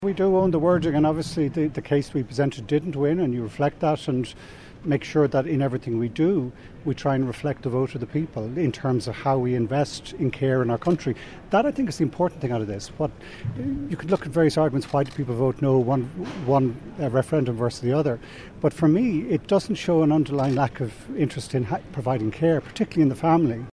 Speaking at the Dublin City count centre in the RDS, he doesn’t accept the campaign by the Green Party went wrong: